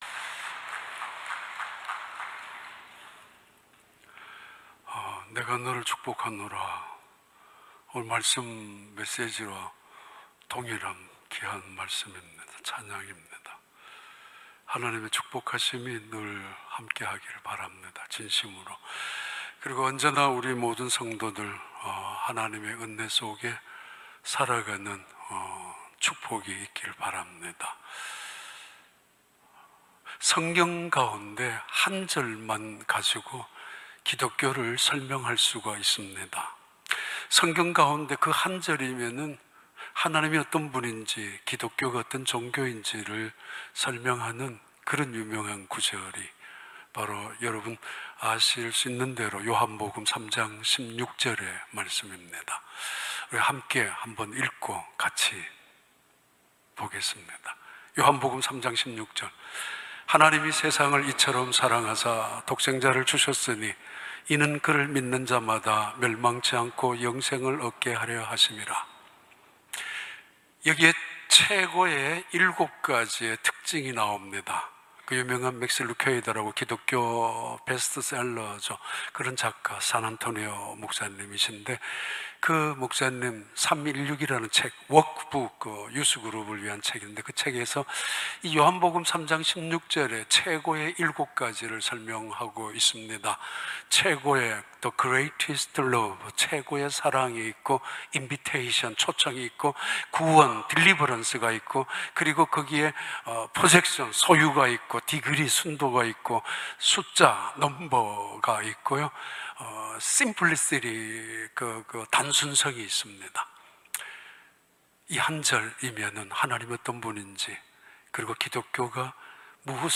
2021년 8월 29일 주일 3부 예배